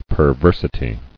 [per·ver·si·ty]